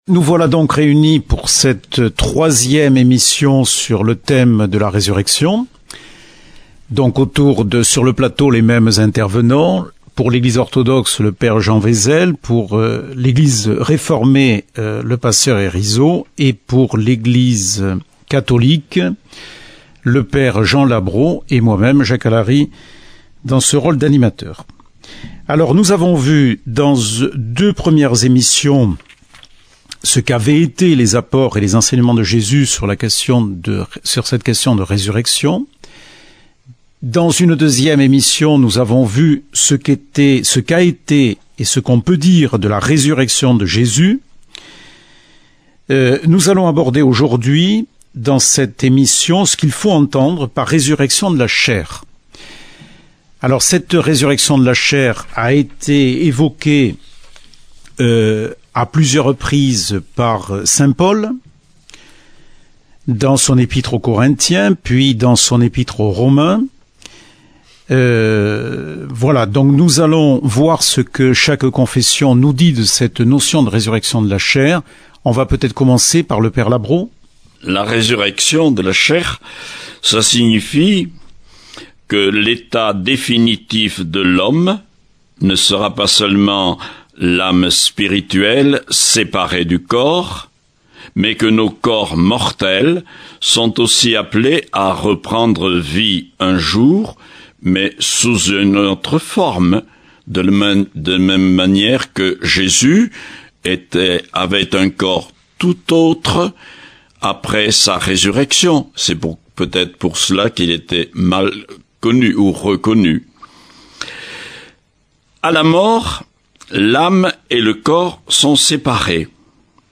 Table ronde suite - La Résurrection